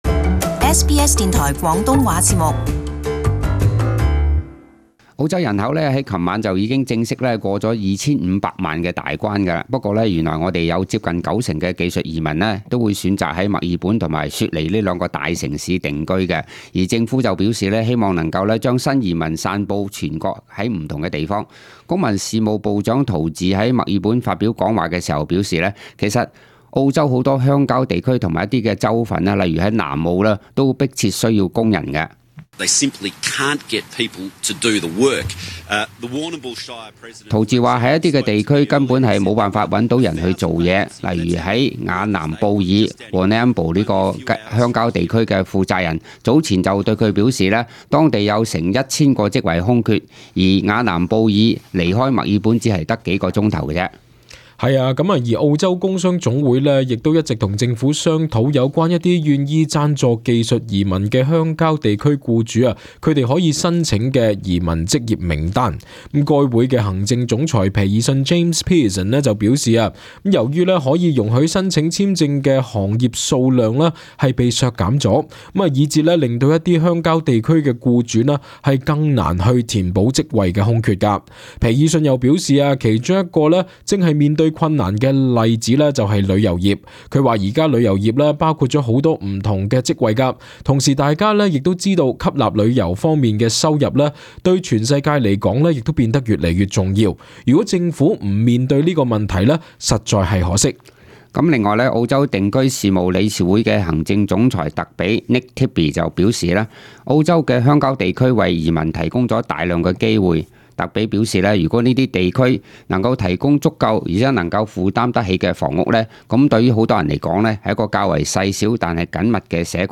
【時事報導】澳洲人口已經正式超過 2500萬大關